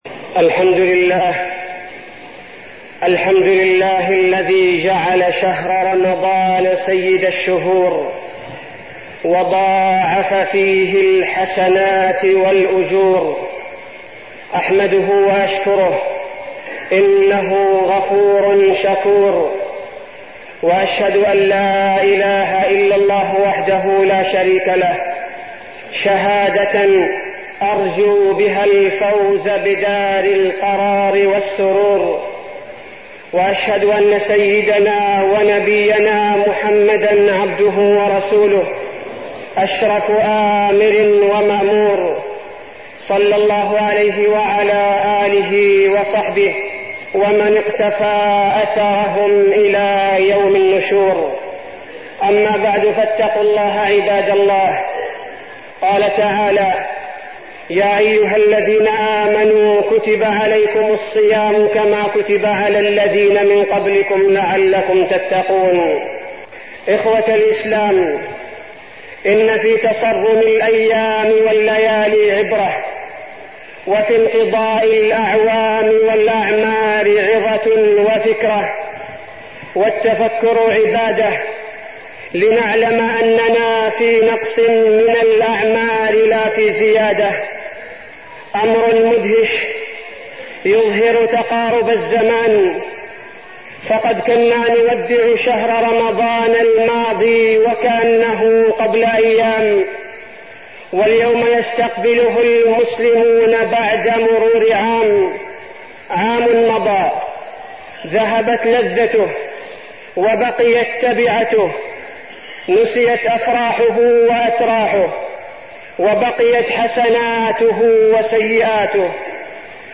تاريخ النشر ١٨ رمضان ١٤١٥ هـ المكان: المسجد النبوي الشيخ: فضيلة الشيخ عبدالباري الثبيتي فضيلة الشيخ عبدالباري الثبيتي فضائل شهر رمضان